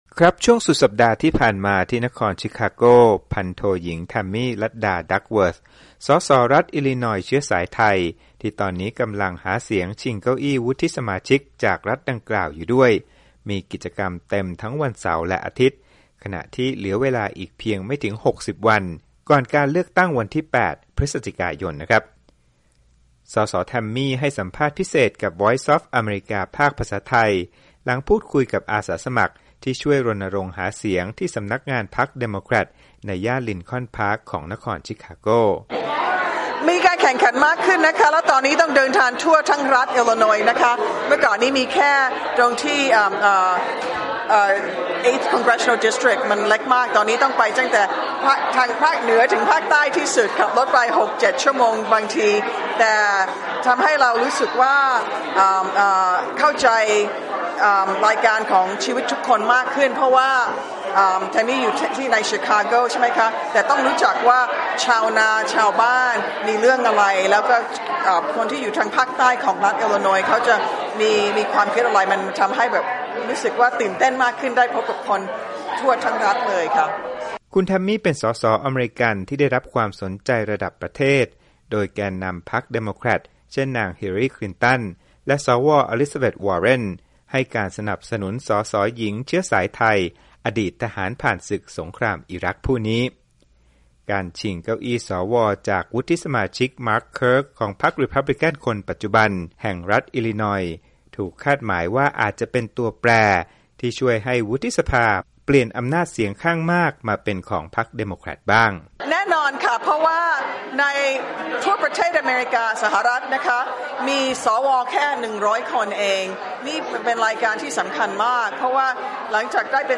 ส.ส.แทมมี่ ให้สัมภาษณ์พิเศษกับวีโอเอไทย หลังพูดคุยกับอาสาสมัครที่ช่วยรณรงค์หาเสียงที่สำนักงานพรรคเดโมเครตในย่านลินคอนพาร์คของนครชิคาโก